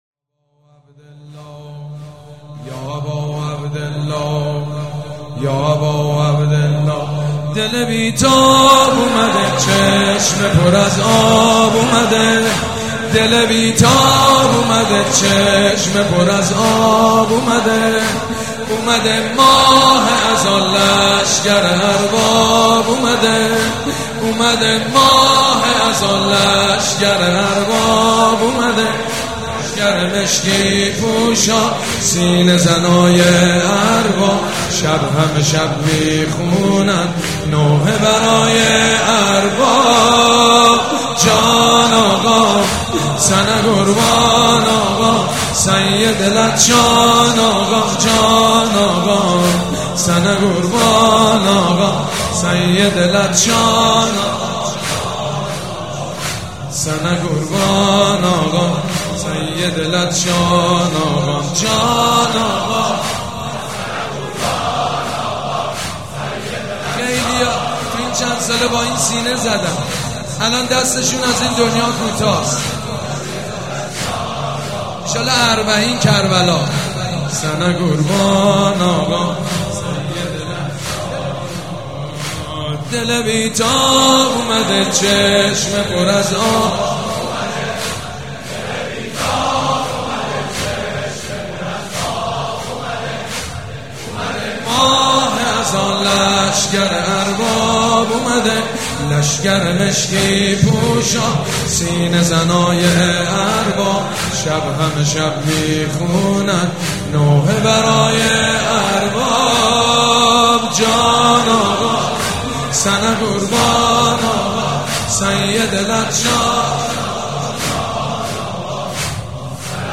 دسته بندی :تواشیح , صوت , مدیحه سرایی , مذهبی , مرثیه سرایی
دانلود مداحی و روضه خوانی شب هشتم ماه محرم در سال 1396